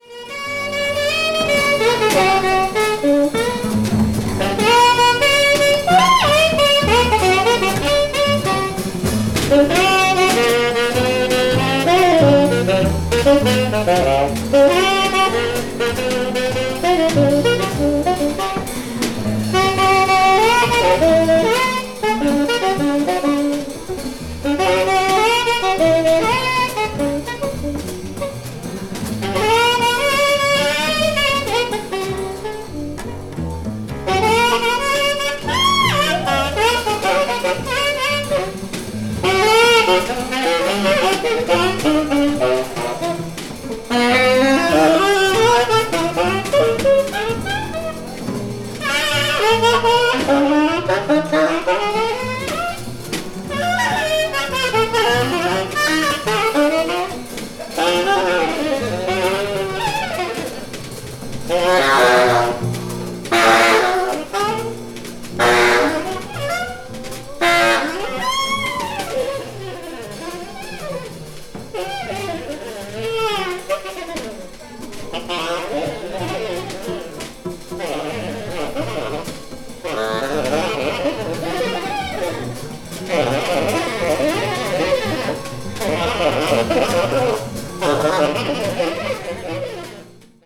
media : EX-/EX-(light noise caused by slightly hairlines.)
avant-jazz   free improvisation   free jazz   spiritual jazz